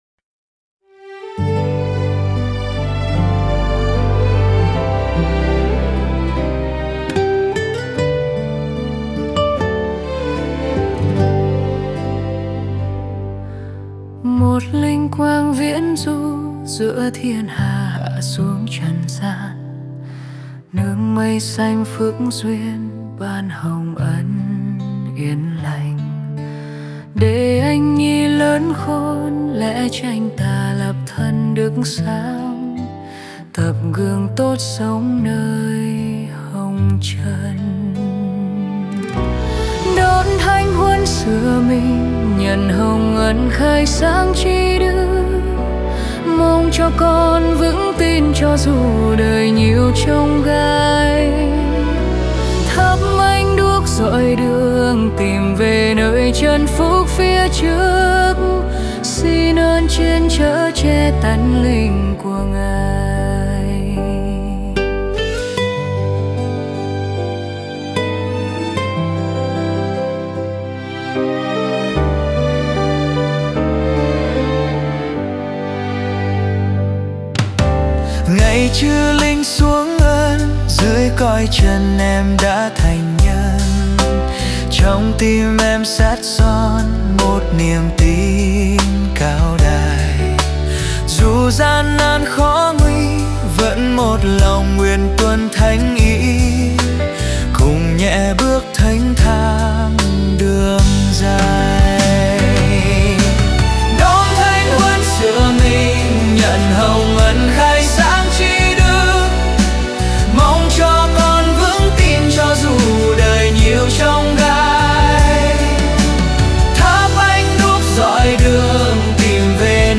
Tone G
•   Vocal  01.